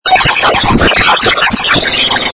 какаято девка чето поет на испанском или мексиканском )))